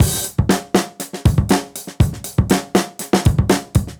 Index of /musicradar/dusty-funk-samples/Beats/120bpm
DF_BeatD_120-01.wav